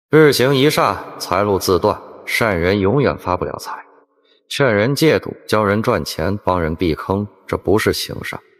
Urzekaj słuchaczy kojącym, niebiańskim głosem AI zaprojektowanym do codziennych odczytów, astrologicznych spostrzeżeń i opowieści.
Tekst na mowę
Kojąca narracja
Mistyczny ton
Charakteryzuje się naturalną kadencją i ekspresyjną intonacją dostosowaną do treści duchowych.